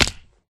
hurtflesh.ogg